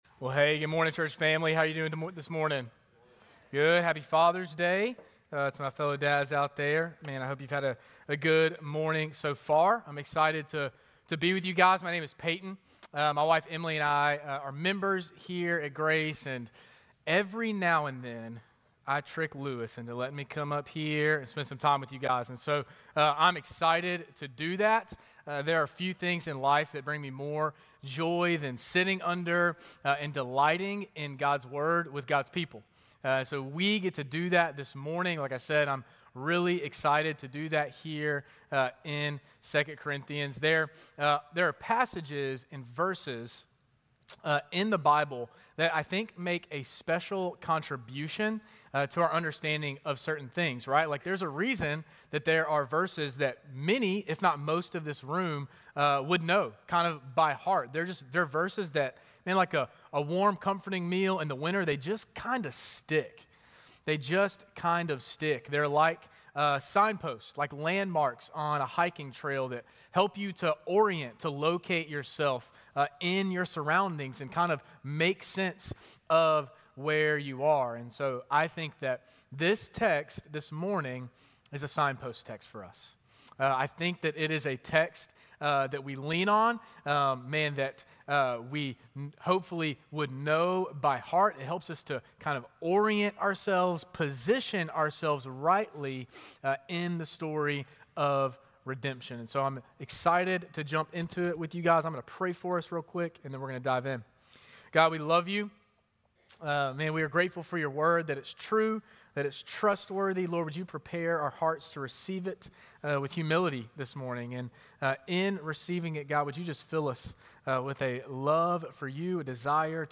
Grace Community Church Lindale Campus Sermons 6_15 Lindale Campus Jun 16 2025 | 00:33:15 1x 00:00 / 00:33:15 Subscribe Share RSS Feed Share Link Embed